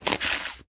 mob / fox / aggro1.ogg